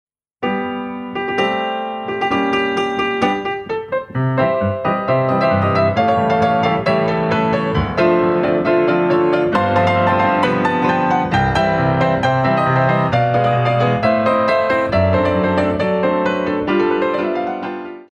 Coda